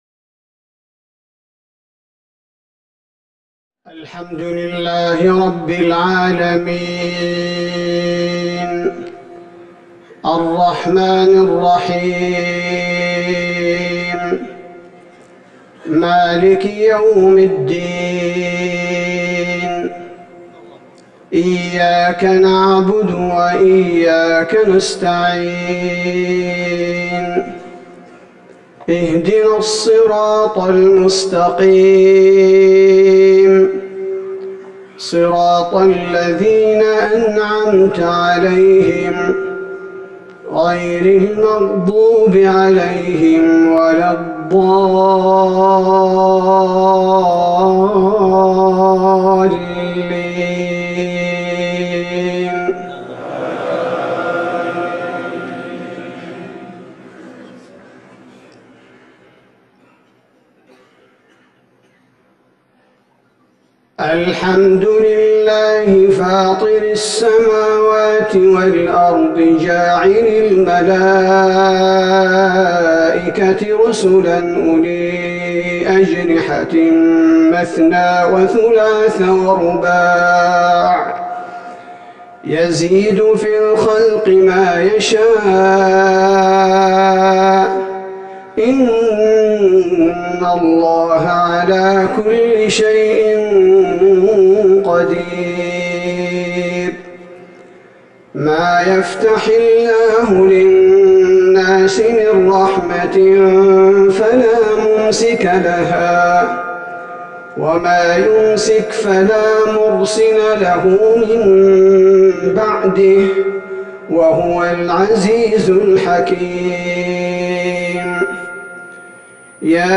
صلاة العشاء ١٥ جمادي الاخره ١٤٤١هـ سورة فاطر Isha prayer 9-2-2020 from Surah Fatir > 1441 🕌 > الفروض - تلاوات الحرمين